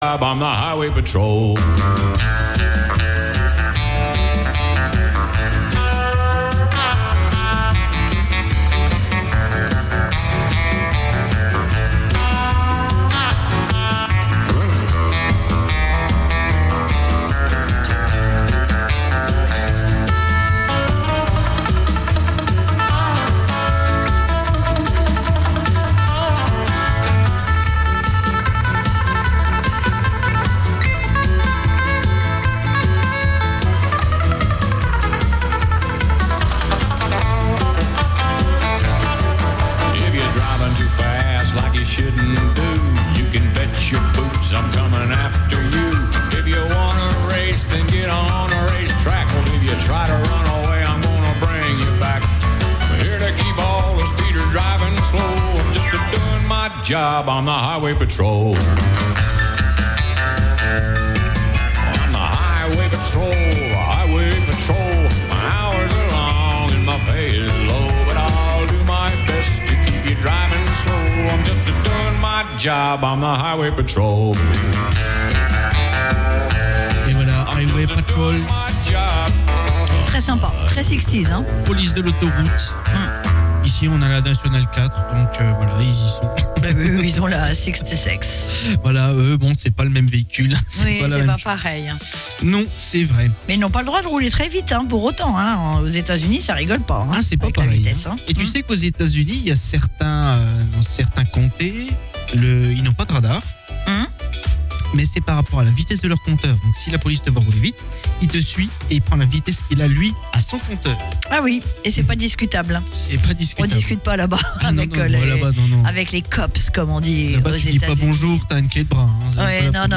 Tous les dimanches de 14h30 à 17h00En direct sur ANTENNE 87À (re)découvrir en podcast sur notre site web